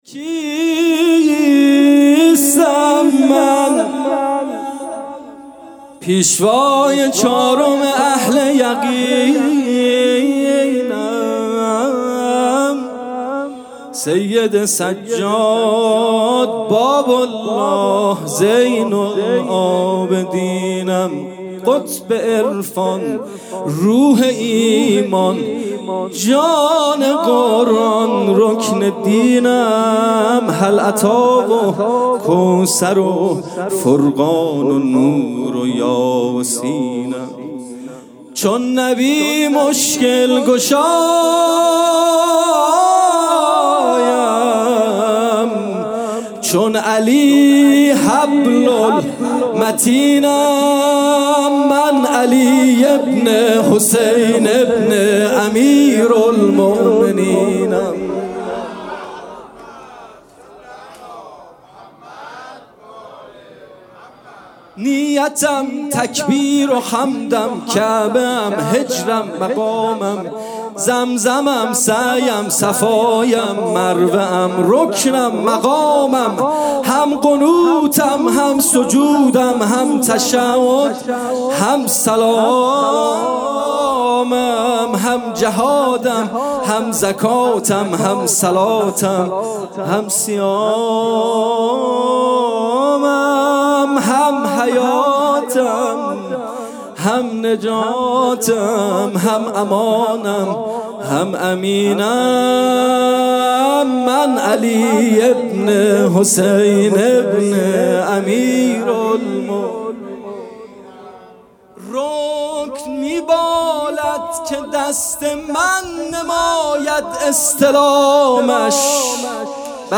جلسه هفتگی
music-icon سرود